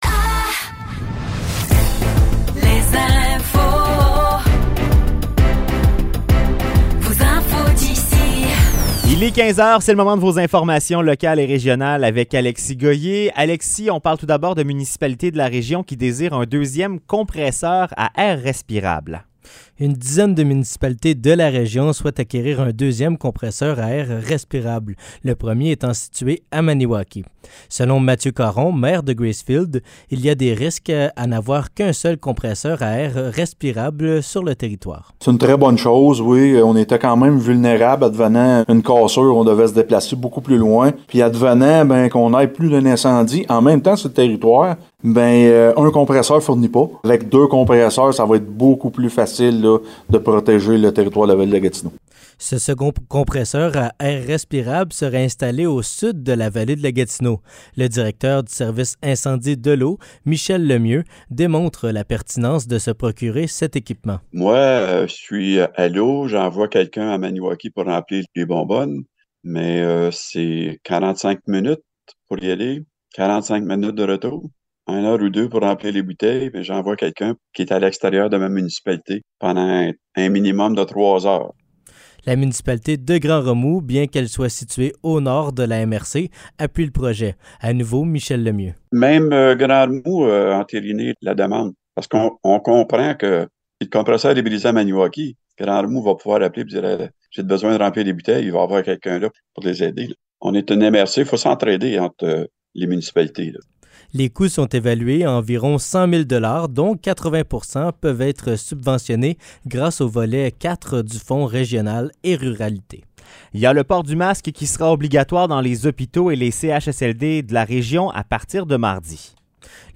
Nouvelles locales - 6 octobre 2023 - 15 h